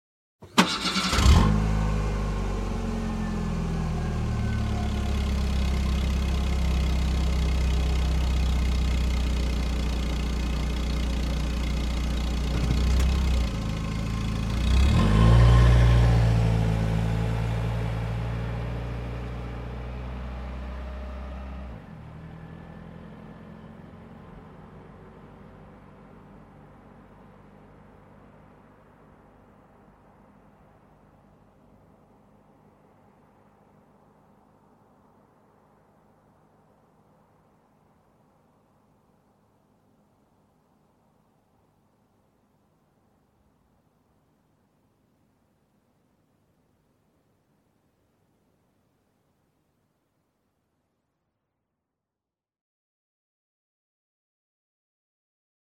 汽车-交通工具-图秀网
图秀网汽车频道，提供汽车音频素材。